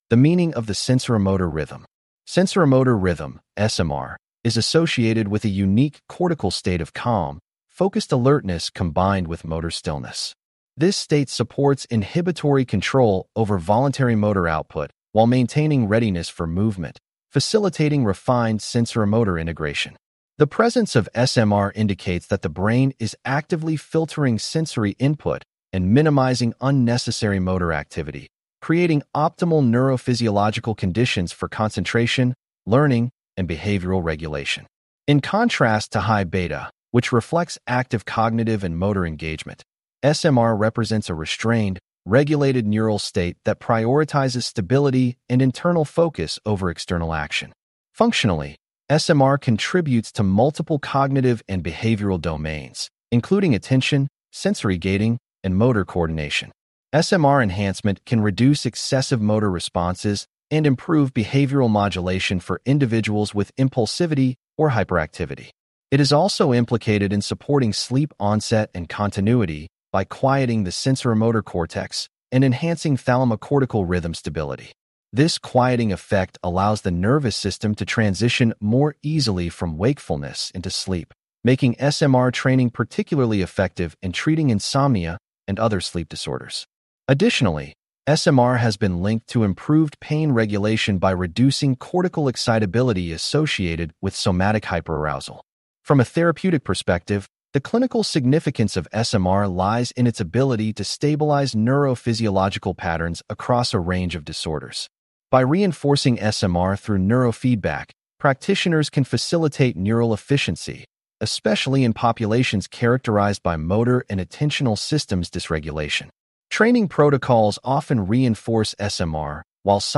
This unit covers Analog, Raw EEG, Basic Signal Measurement Terms, Filtering Methods, Subjective Characteristics of Frequency Bands, Waveform Morphology, Source Localization, and Clinically Significant Waveforms. Please click on the podcast icon below to hear a full-length lecture.